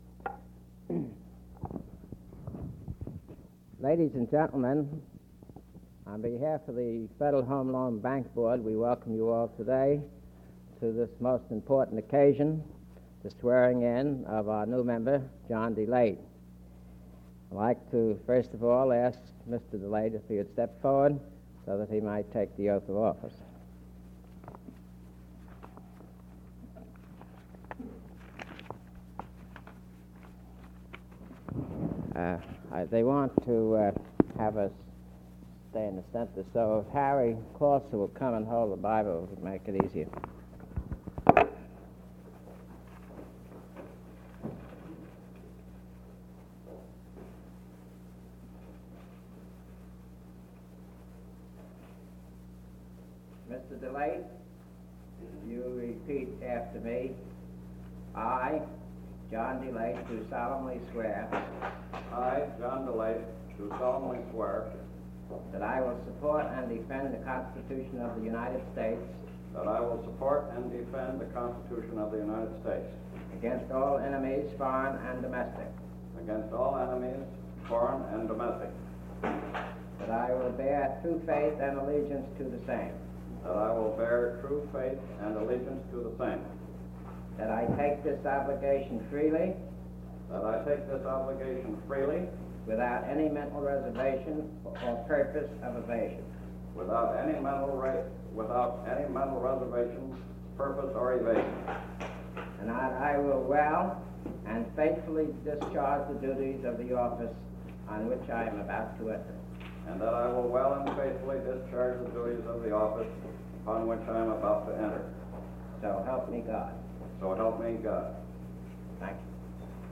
Swearing in ceremony for the Federal Home Bank Board at the National Archives in Washington D.C., September 17, 1962. 1 master sound tape reel (39 minutes, 17 seconds); 7 inches and 1 user audio file: MP3 (26.4 MB).